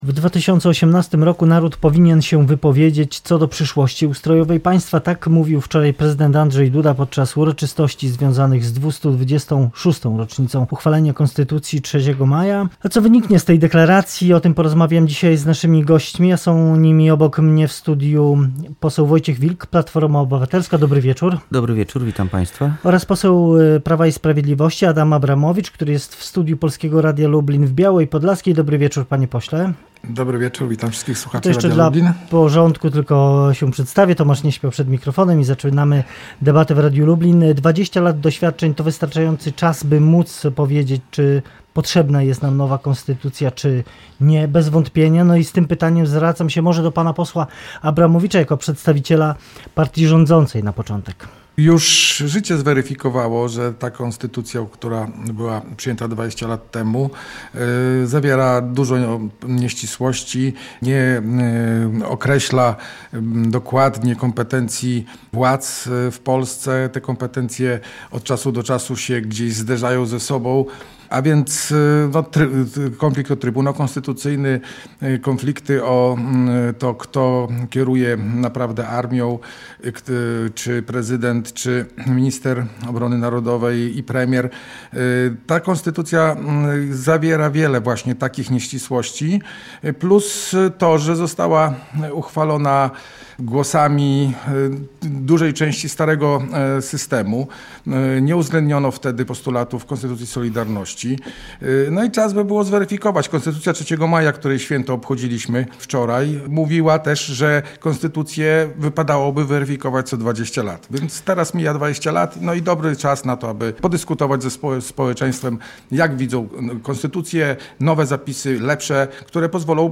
Co oznacza ta deklaracja po 20 latach obowiązywania obecnej ustawy zasadniczej i co z tego wyniknie? O tym w Debacie Radia Lublin